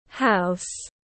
House /haʊs/